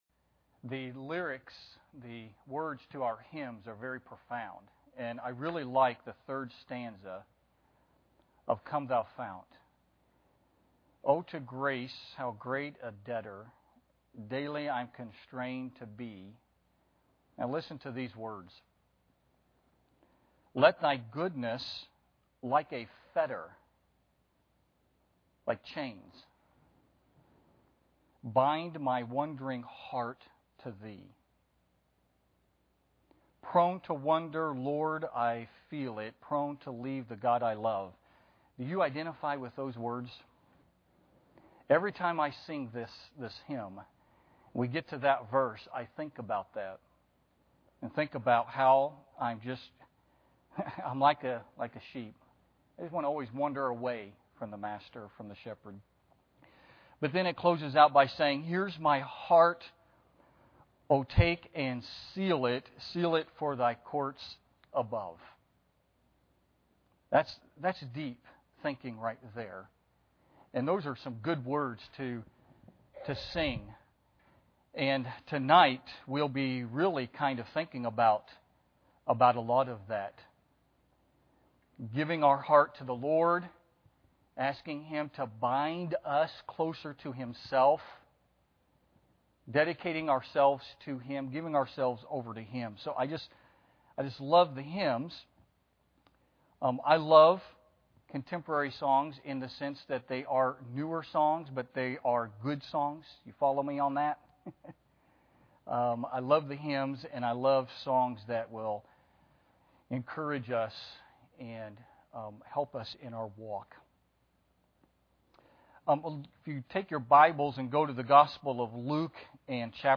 Luke 10:38-42 Service Type: Wednesday Evening Bible Text